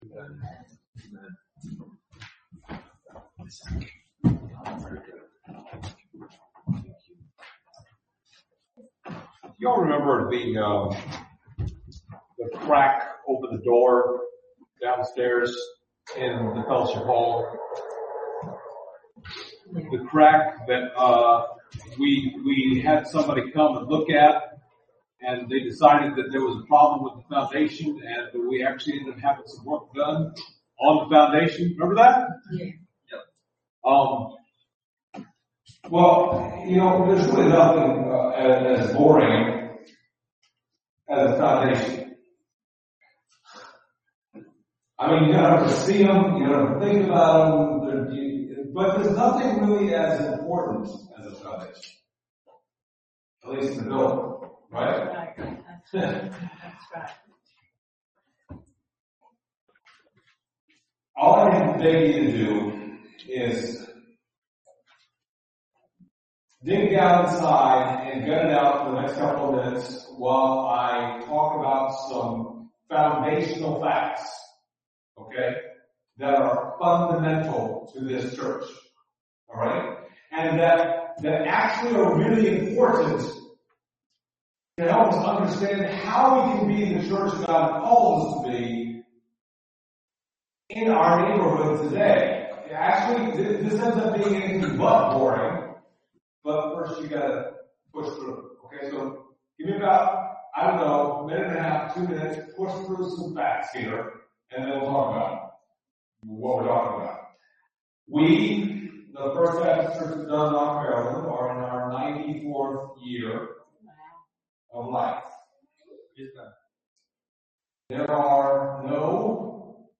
Passage: Acts 6:1-7 Service Type: Sunday Morning